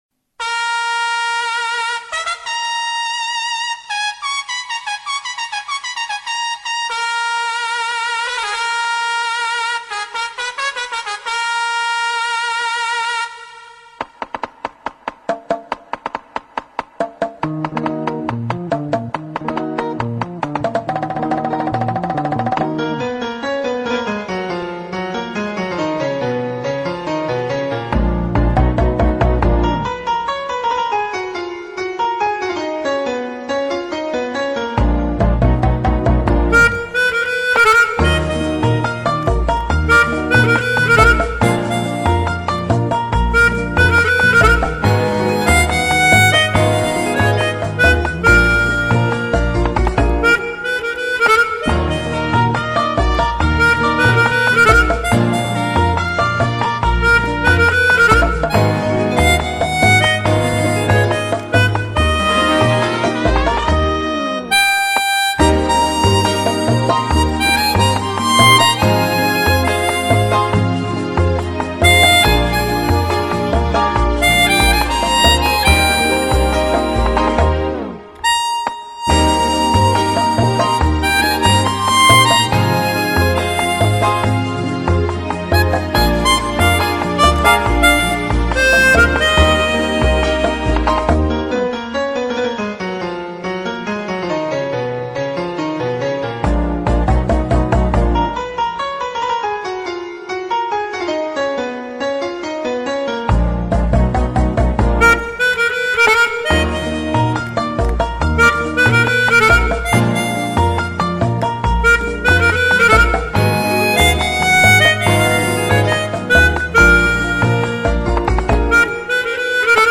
cette superbe rumba